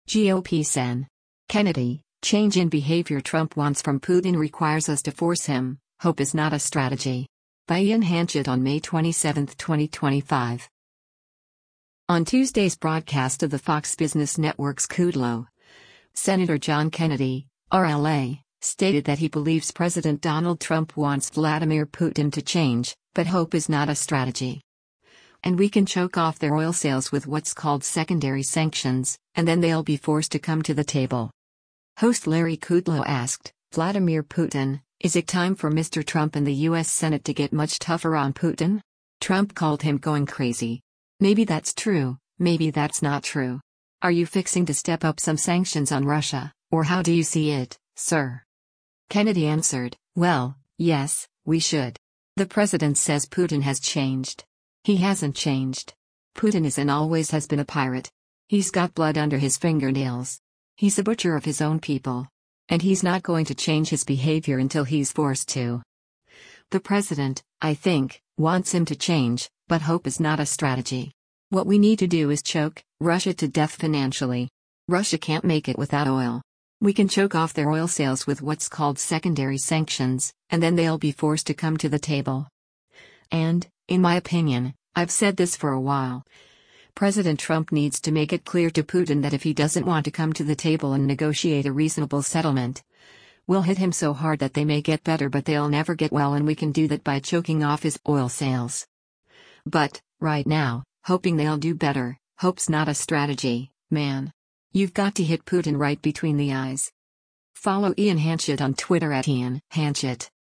On Tuesday’s broadcast of the Fox Business Network’s “Kudlow,” Sen. John Kennedy (R-LA) stated that he believes President Donald Trump wants Vladimir Putin “to change, but hope is not a strategy.” And “We can choke off their oil sales with what’s called secondary sanctions, and then they’ll be forced to come to the table.”